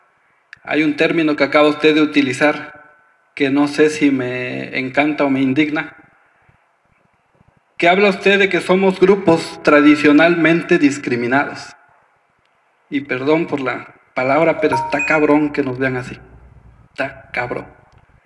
Durante la comparecencia de la titular de la CEDH ante el Congreso del Estado, el legislador quien se dijo «orgullosamente indígena» lamentó la respuesta que le dio la presidenta Namiko Matzumoto Benítez.